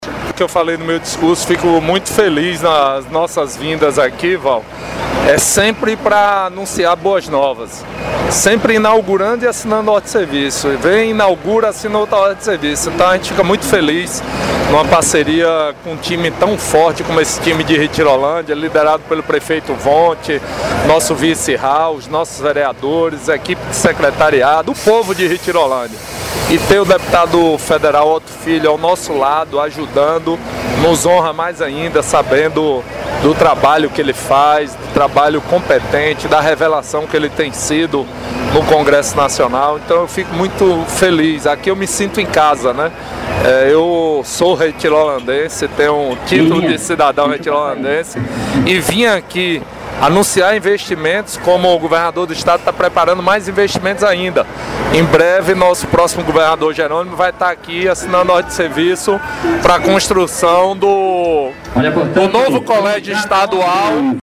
O deputado estadual disse ao IB que era com grande alegria em retornar para Retirolândia e ver obras chegando para melhorar a vida da população.